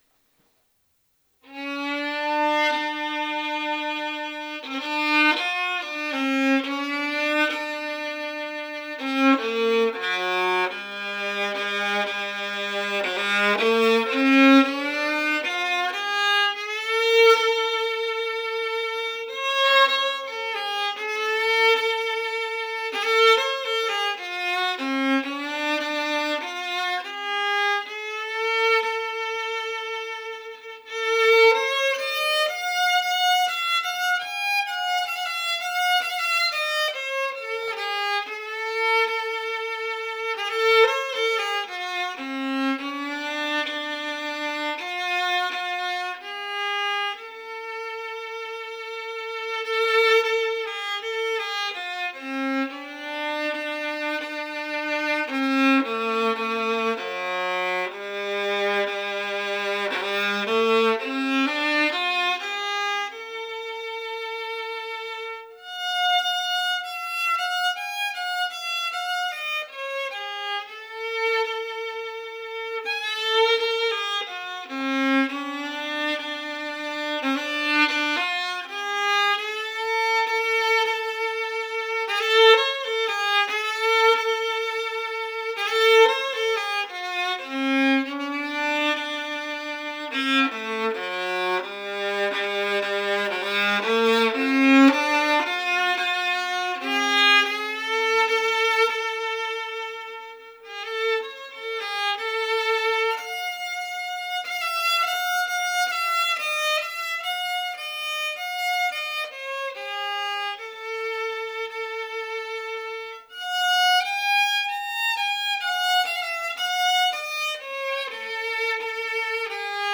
Hardangerfiolens klang
Bilden visar min hardangerfiol och ljudfilen visar hur instrumentet låter.
Jag använder Thomastik Dominant strängar vilket inte är helt genuint, egentligen borde sensträngar användas. Fiolen är stämd som en fiol (GDAE) med resonanssträngarna stämda HDF#GA (BDF#GA om amerikansk notering används).
Bandningen är gjord med en Android telefon med an audio app som spelar in med CD kvalitet. Resultatet är ljudmässigt förvånande bra.
Musikexemplet visar tydligt hur resonanssträngarna ändrar klangen i ett resonanssträngat instrument. Musiken är improviserad i stundens ingivelse.
dreaming_harding_fiddle.wav